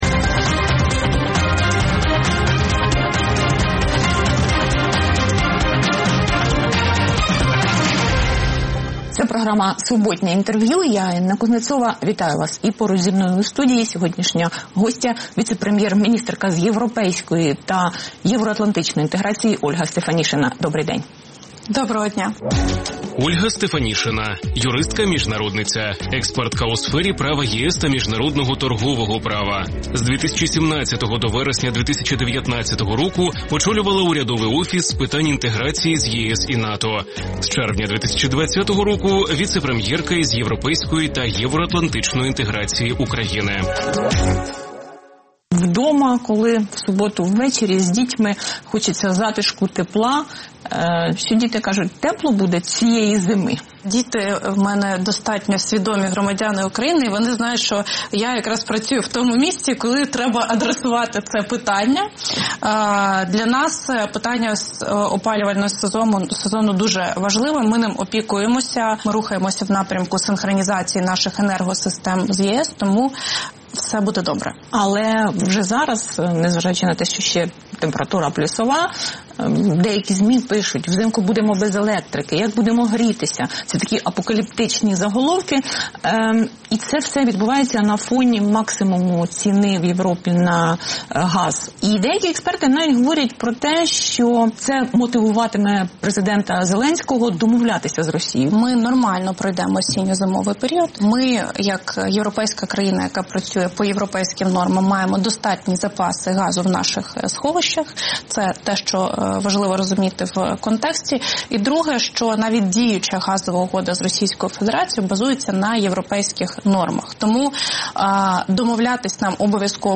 Суботнє інтерв’ю | Ольга Стефанішина, віцепрем’єрка з питань європейської та євроатлантичної інтеграції України
Суботнє інтвер’ю - розмова про актуальні проблеми тижня. Гість відповідає, в першу чергу, на запитання друзів Радіо Свобода у Фейсбуці